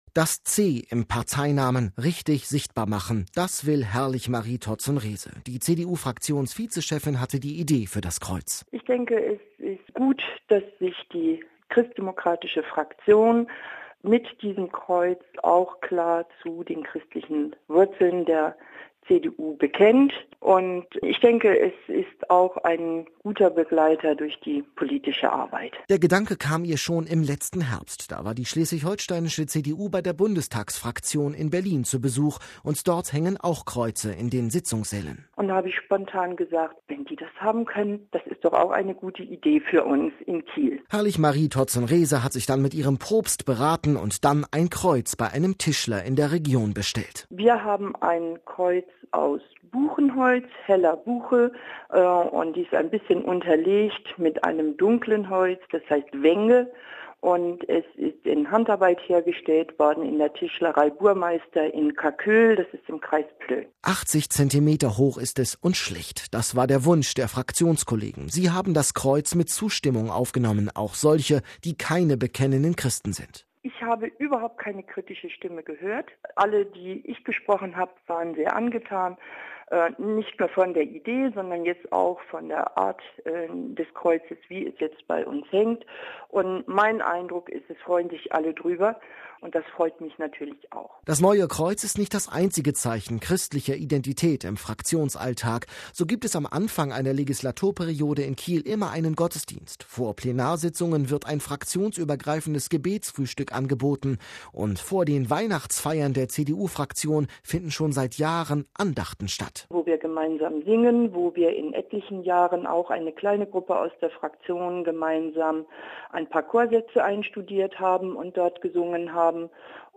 berichtet aus Hamburg.